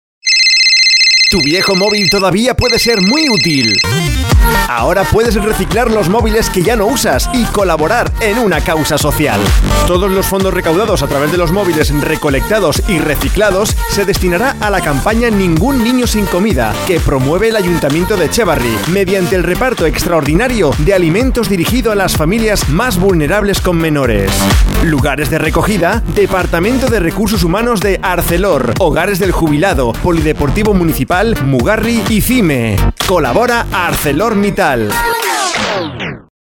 CUÑAS PUBLICITARIAS